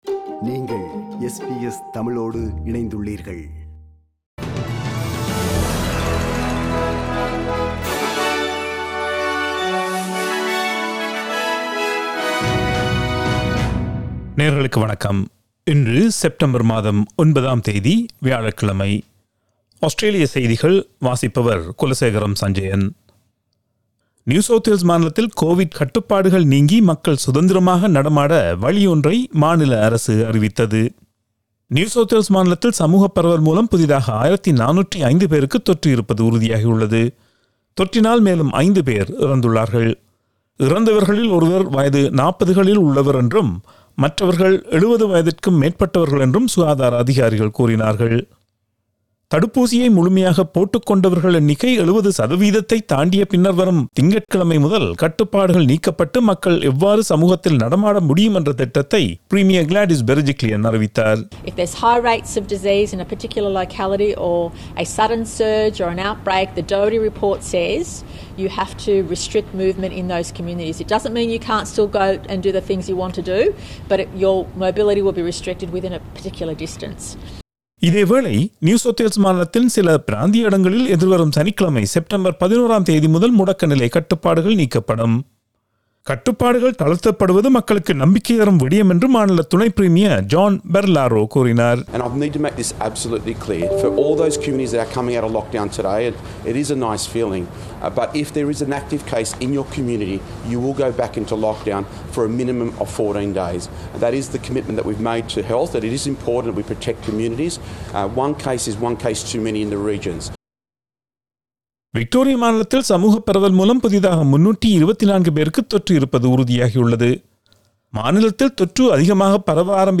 SBS தமிழ் ஒலிபரப்பின் இன்றைய (வியாழக்கிழமை 09/09/2021) ஆஸ்திரேலியா குறித்த செய்திகள்.